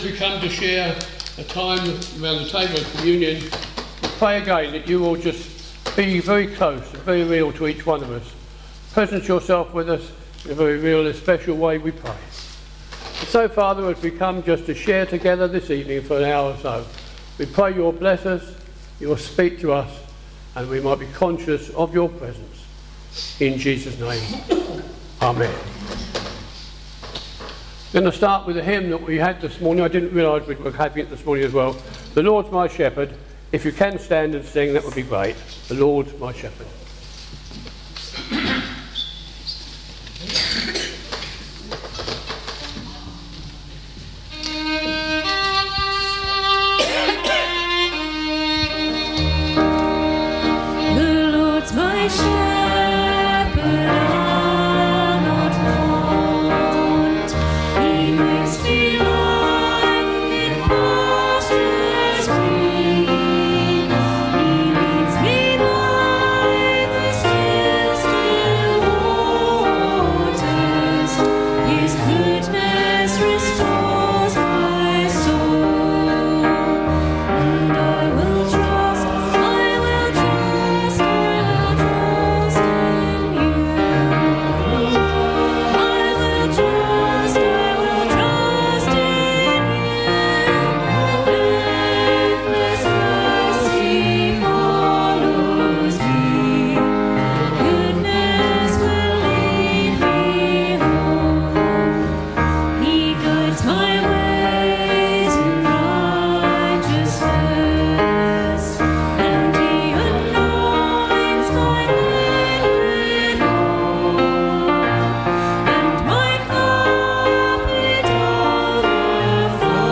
A Reflective service - Sittingbourne Baptist Church
This service includes Communion.
Service Audio